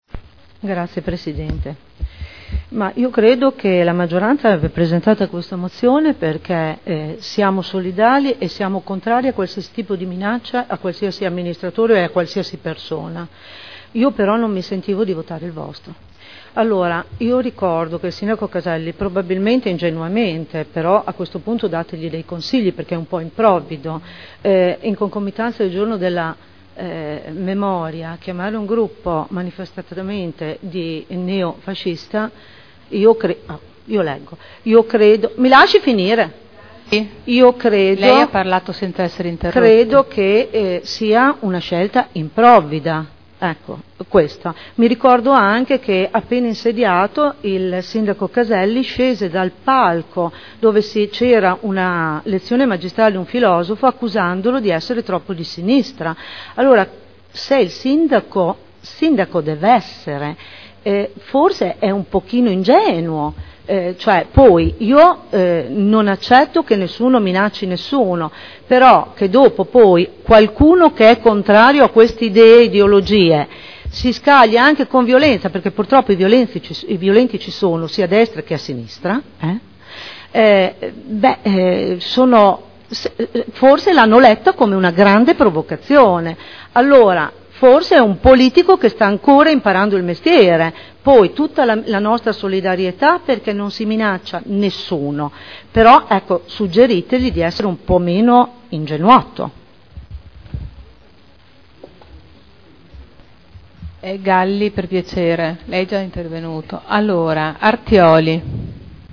Seduta del 12/04/2012. Dibattito sull' Ordine del giorno 13516 e Mozione 42048.